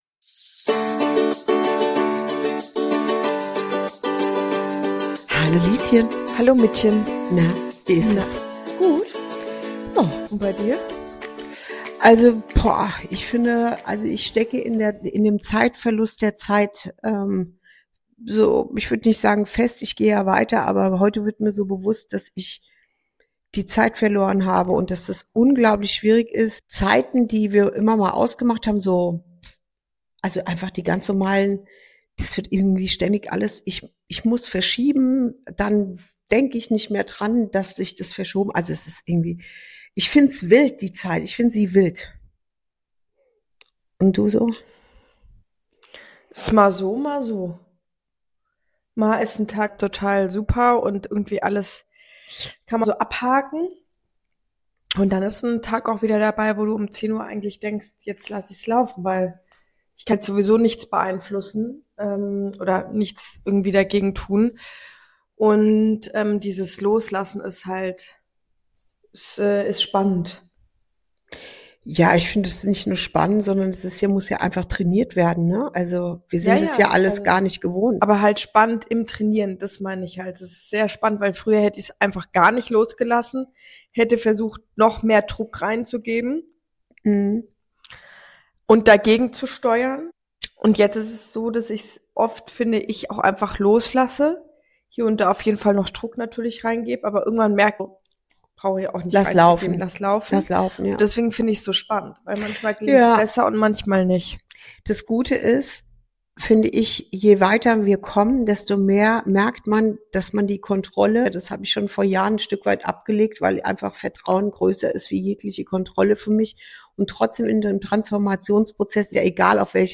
Ein ehrliches, tiefes Gespräch über die Sprache des Körpers, emotionale Bindung, innere Heimat und die Sehnsucht nach einem Leben im Einklang mit sich selbst.